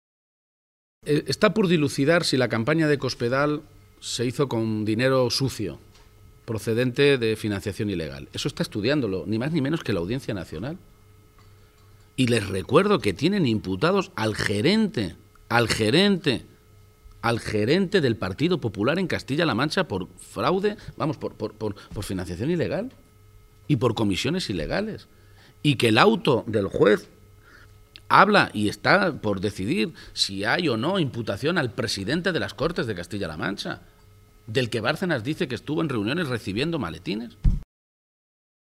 Emiliano García-Page durante el desayuno informativo celebrado en Cuenca